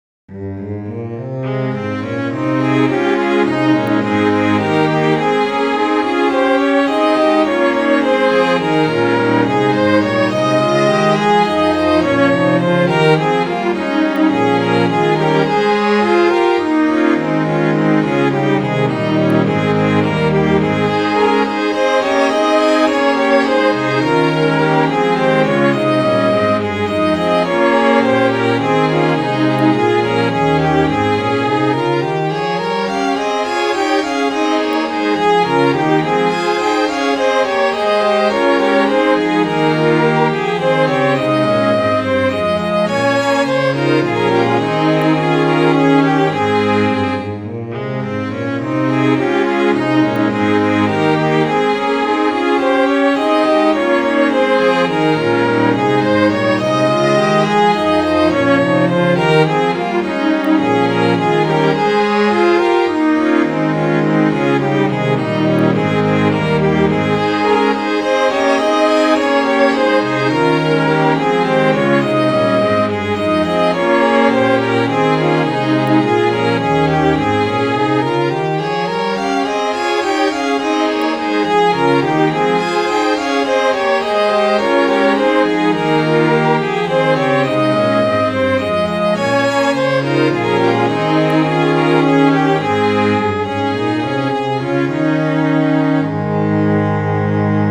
Midi File, Lyrics and Information to A Brisk Young Sailor